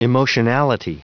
Prononciation du mot : emotionality